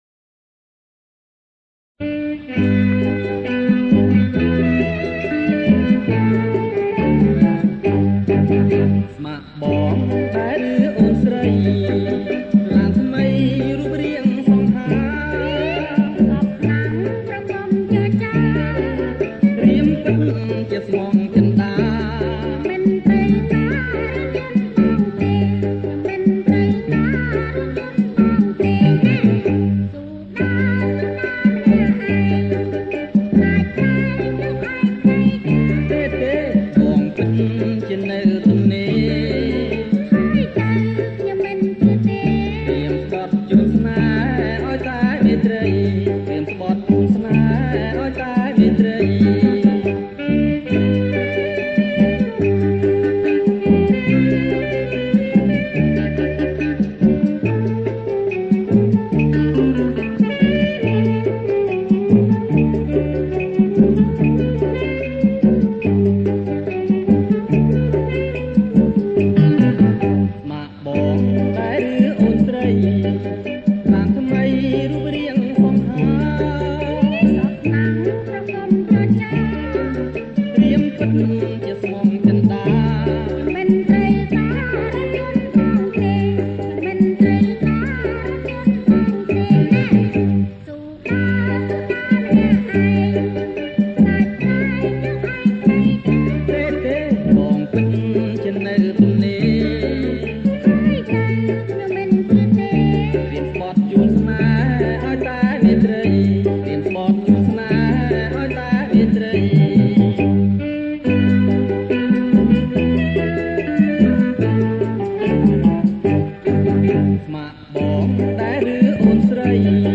ប្រគំជាចង្វាក់ រាំវង់
ថតផ្ទាល់ពីថាស Vinyl